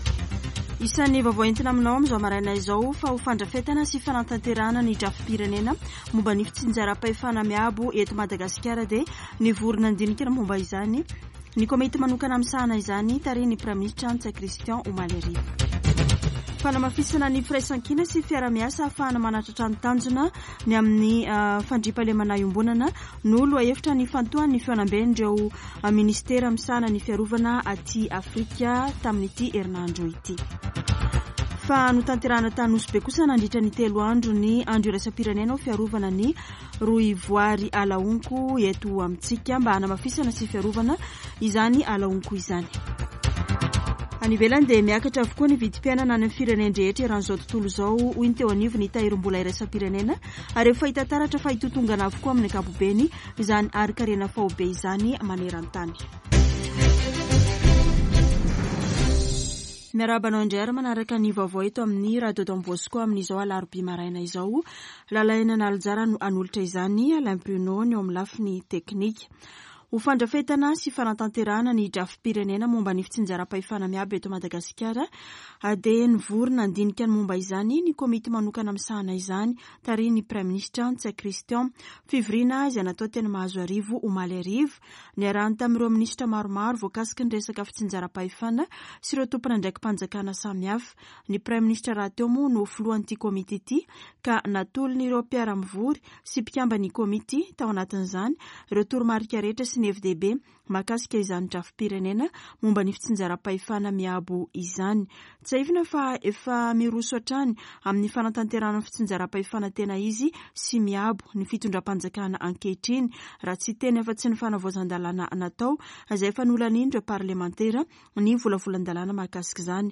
[Vaovao maraina] Alarobia 27 jolay 2022